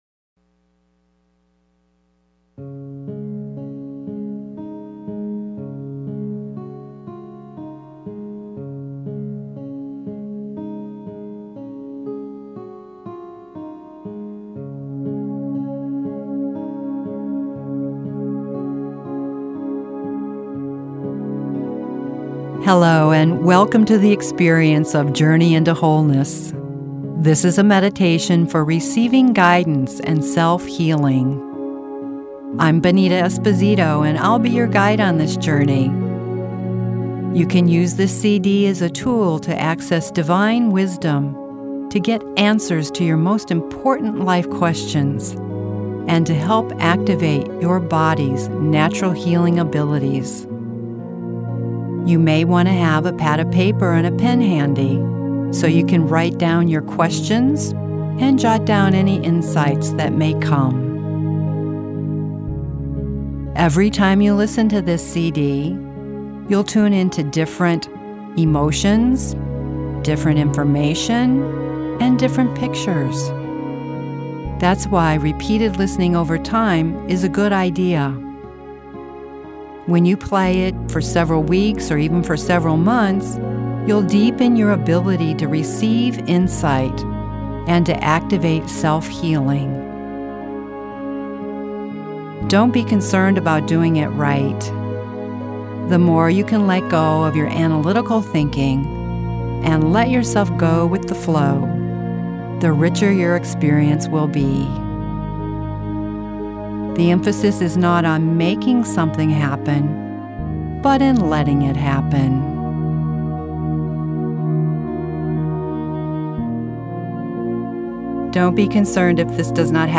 With every slow gentle note, you will soften into the security of spiritual intimacy, feeling enfolded in God’s love.
Listen to the soothing words and music while pictures play across your mind to bring you answers to your most important life questions.